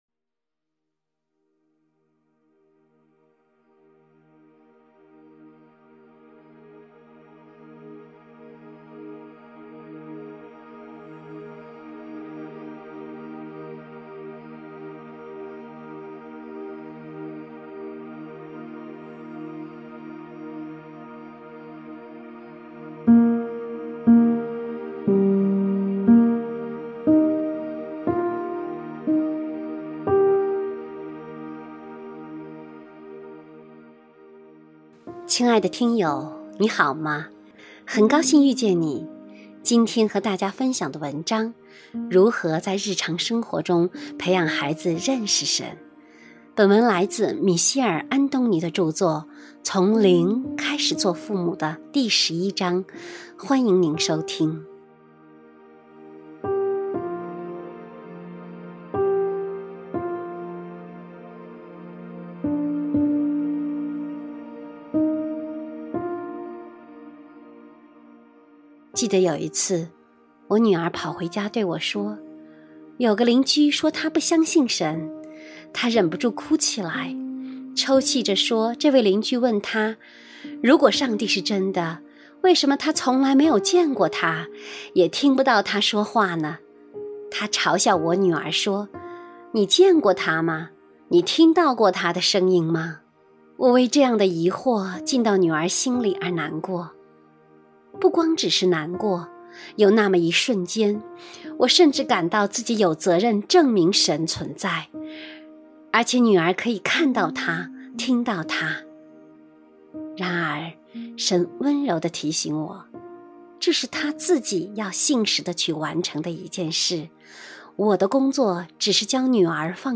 首页 > 有声书 > 婚姻家庭 > 单篇集锦 | 婚姻家庭 | 有声书 > 如何在日常生活中培养孩子认识神？